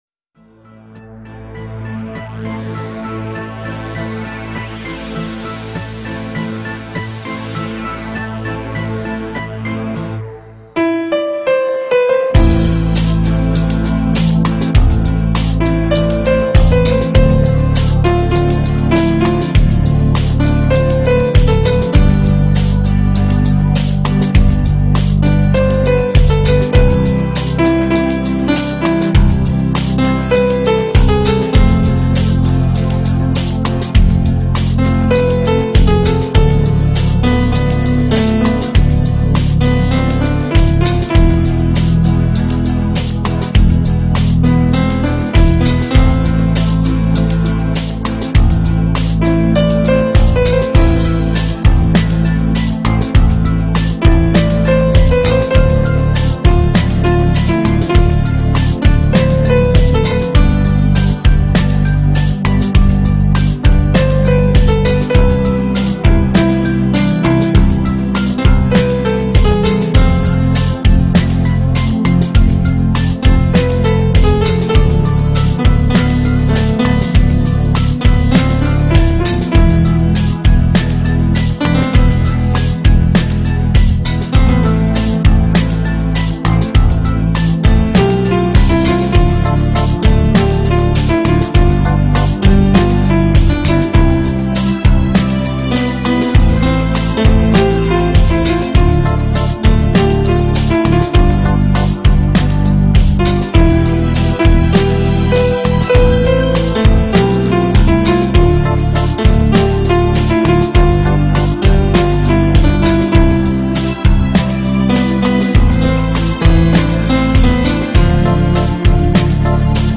Красивый инструментал